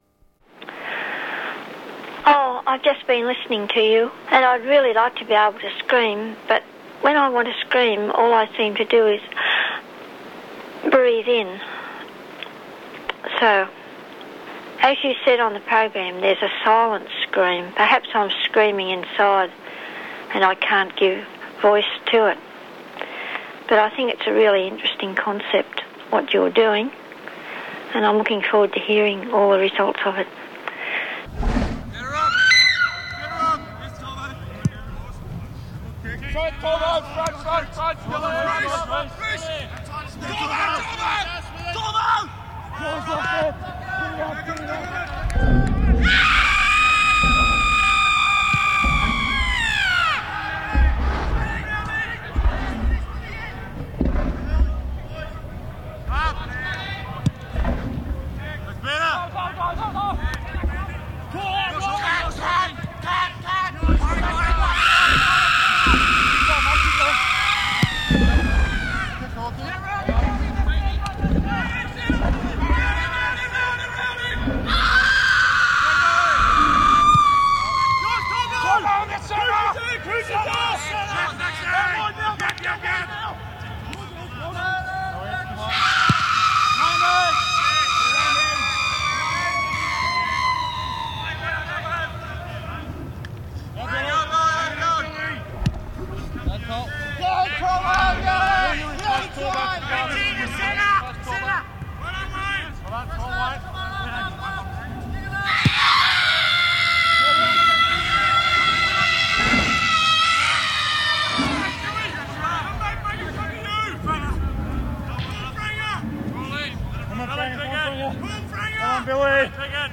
Lubricated by discourse and publicity, the scream trickle soon became a flood, in both the scream room and on the screamline, and the producer “nodes” at the Institute began to feel the first effects of The Pressure. In addition to framing the nervous system, the telephone-microphone-tape recorder-radio circuitry also provided the key for the acoustic demarcation of pressure in the system: distortion, the disruption of digital codes inside the scream room; pure unmanageable noise.